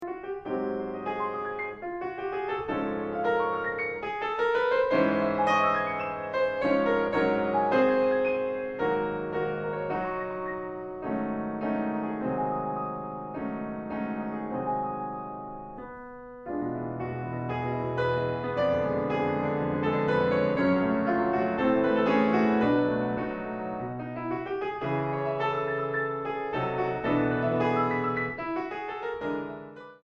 Allegro tranquillo 1.15